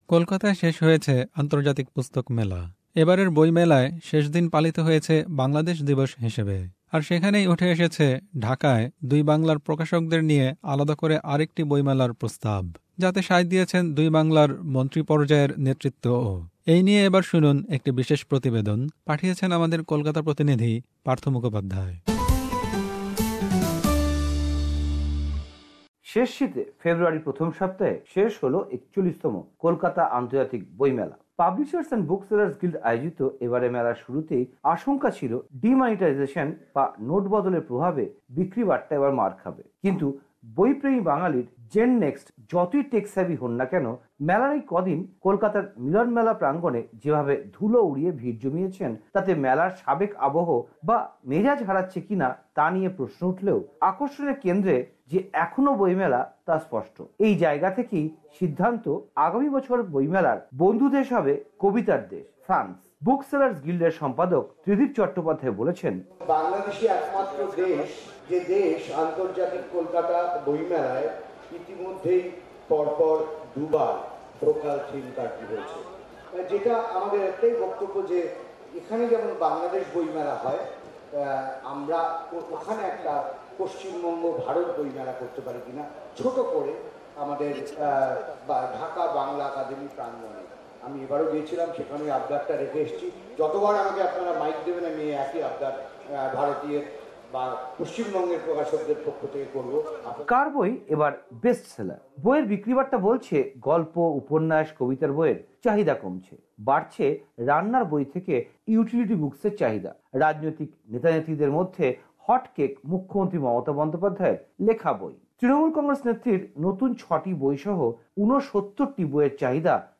Report: Kolkata Book Fair